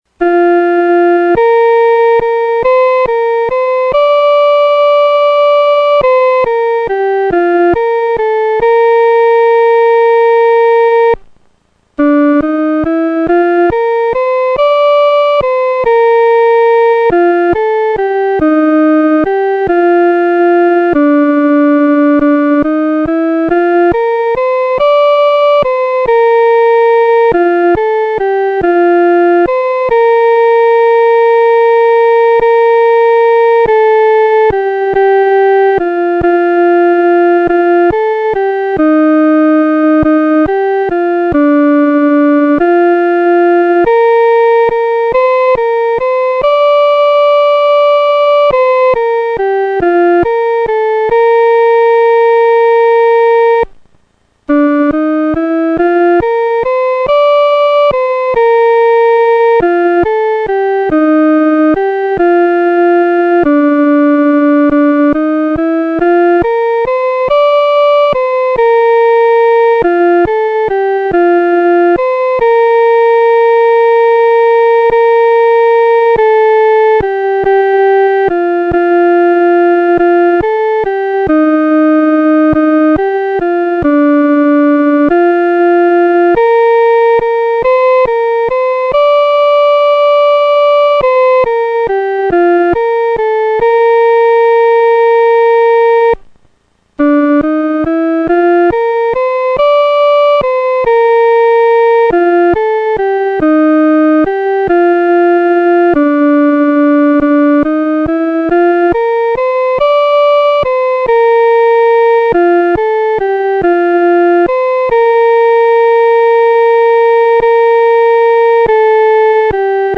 独奏（第一声）
天父必看顾你-独奏（第一声）.mp3